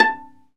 Index of /90_sSampleCDs/Roland - String Master Series/STR_Viola Solo/STR_Vla Pizz